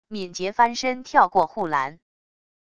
敏捷翻身跳过护栏wav音频